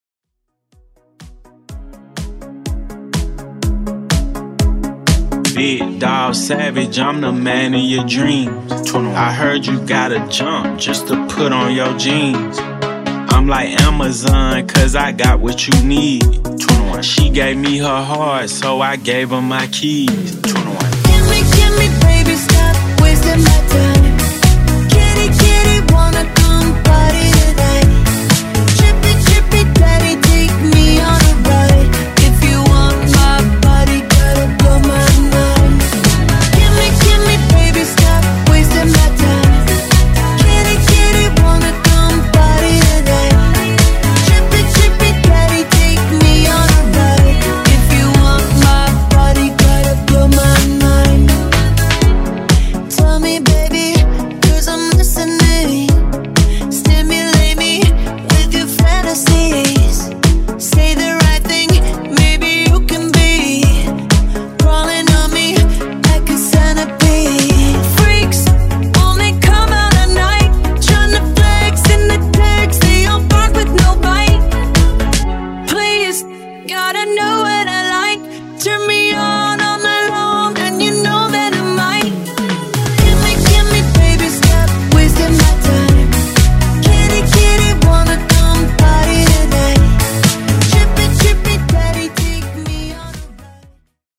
Genres: EDM , MASHUPS , TOP40
Clean BPM: 130 Time